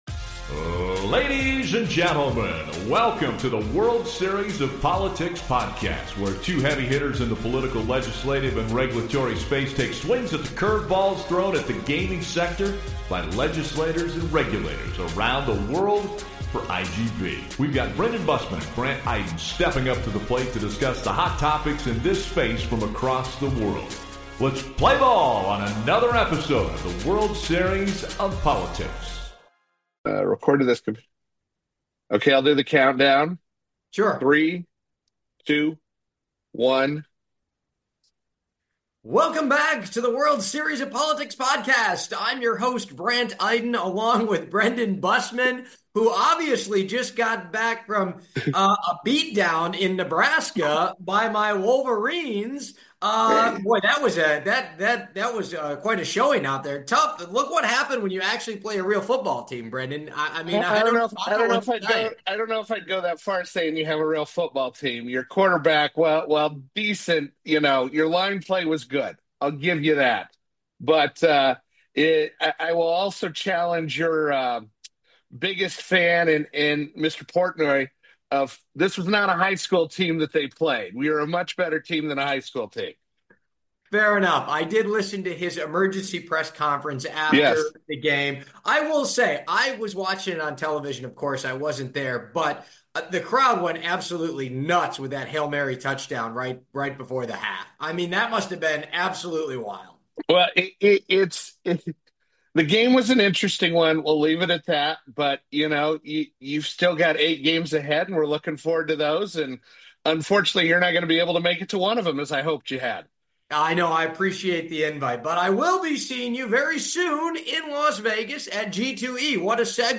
A new iGB podcast featuring two of the foremost regulatory affairs pros aims to offer provide exclusive insights on the work that goes into passing gambling legislation around the world.